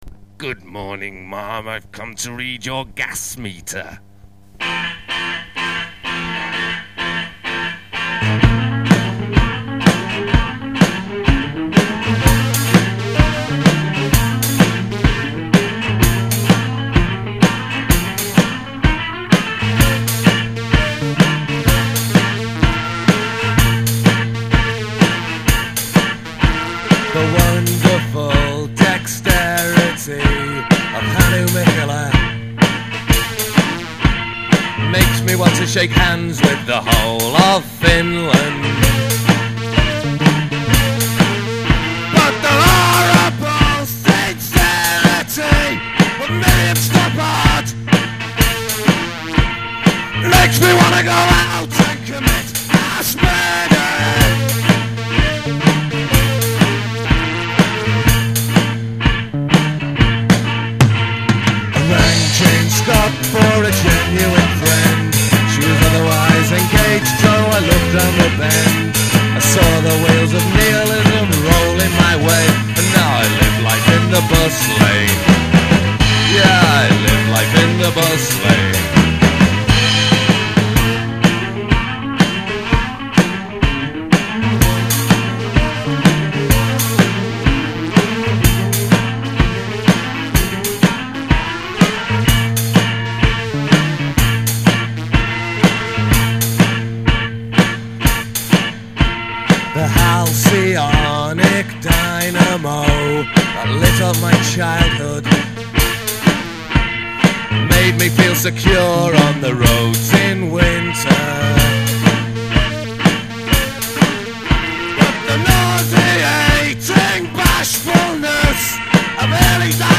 Género: Alternative.